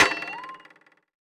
playerLongJump.wav